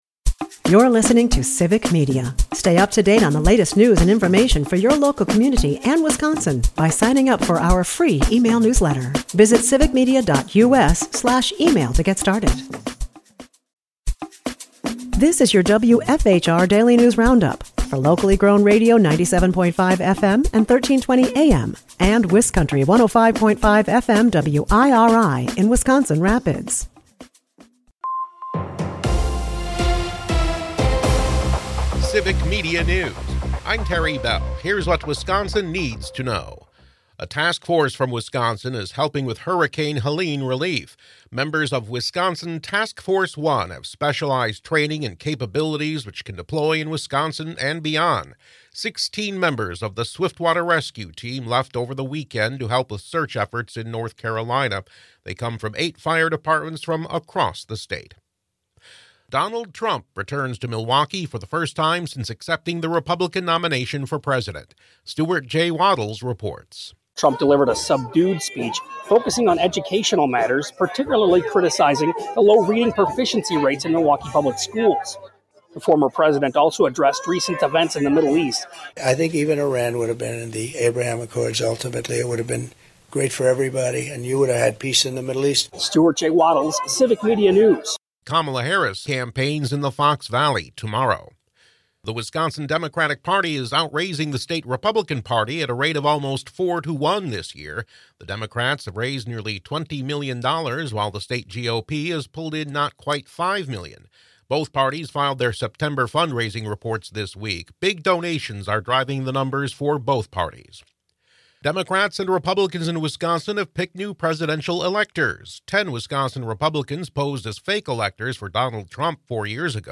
The WFHR Daily News Roundup has your state and local news, weather, and sports for Wisconsin Rapids, delivered as a podcast every weekday at 9 a.m. Stay on top of your local news and tune in to your community!